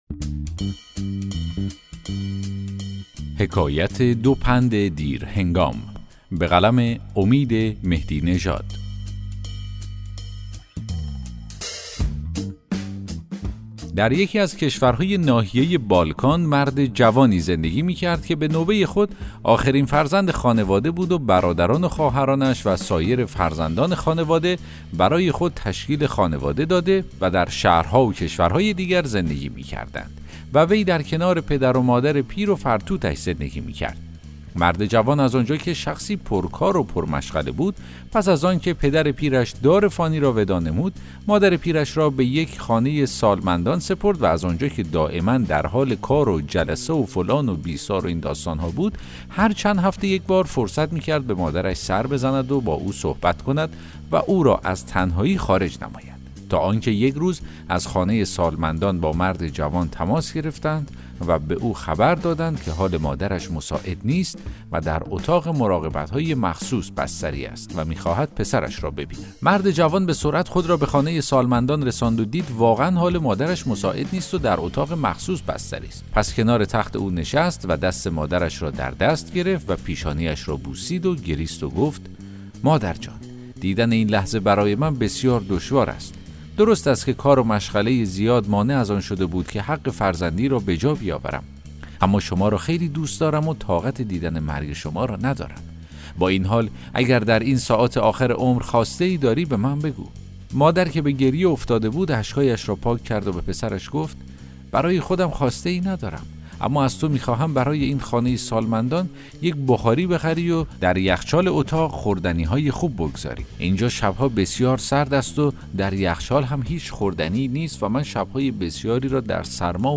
داستان صوتی: حکایت دو پند دیرهنگام